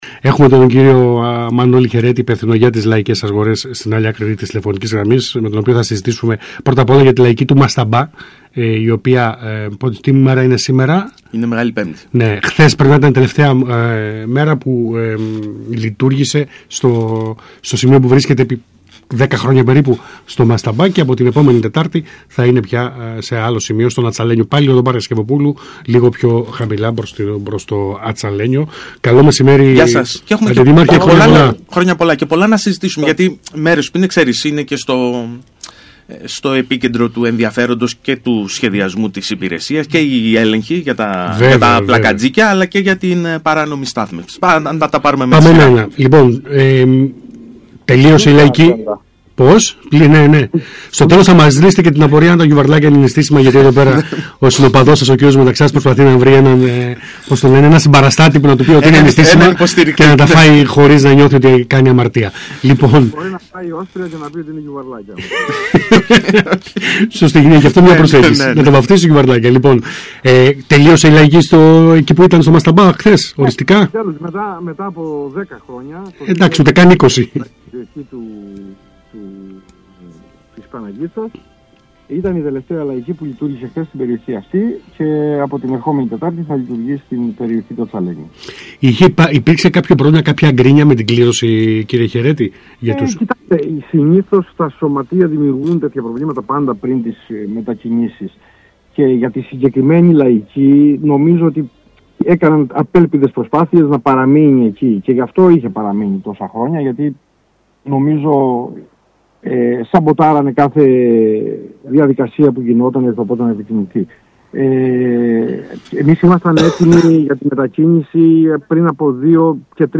Ακούστε εδώ όσα είπε ο Αντιδήμαρχος Μανώλης Χαιρέτης στον ΣΚΑΙ Κρήτης 92.1: